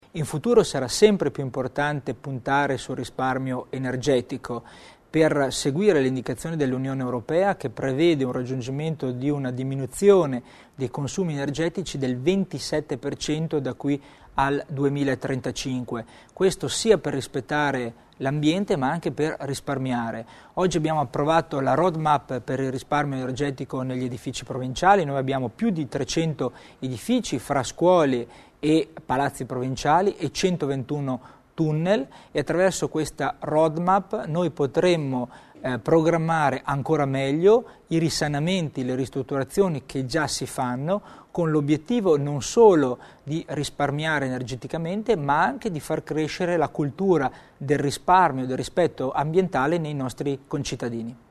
Il Vicepresidente Tommasini spiega le iniziative in tema di risparmio energetico